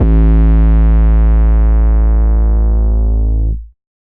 808 Kick 7_DN.wav